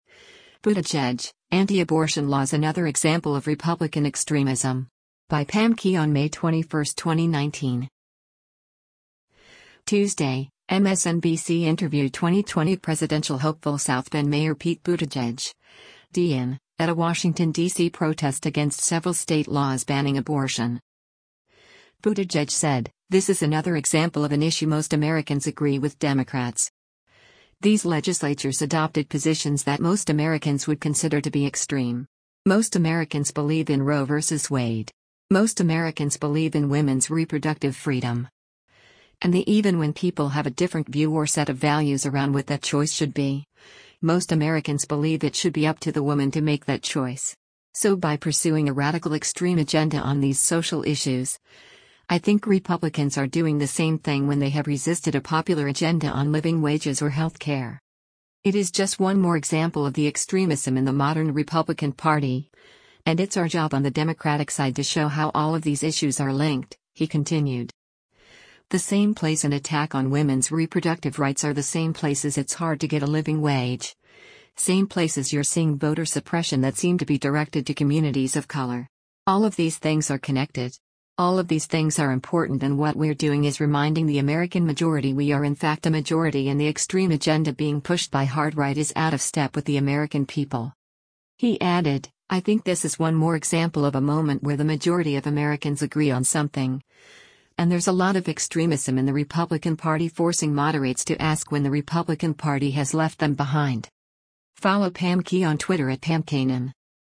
Tuesday, MSNBC interviewed 2020 presidential hopeful South Bend Mayor Pete Buttigieg (D-IN) at a Washington D.C. protest against several state laws banning abortion.